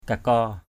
/ka-kɔ:/ (d.) go (trong khung cưởi), dây nhợ = rang de lisses.